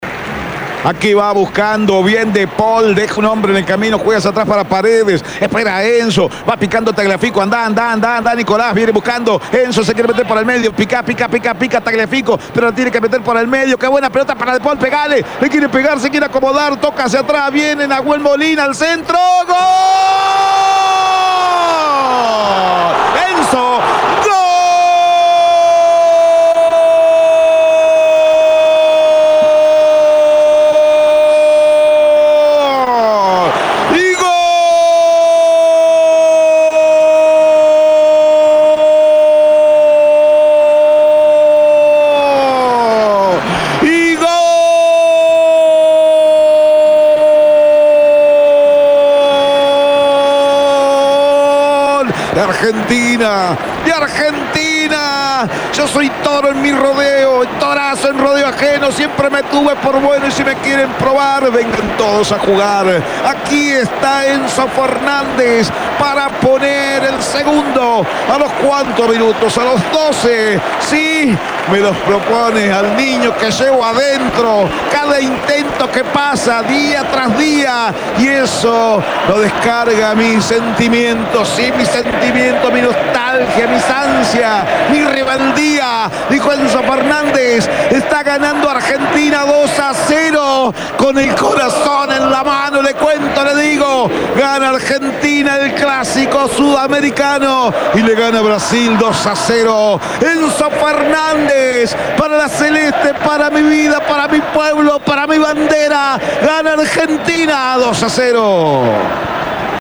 EN EL RELATO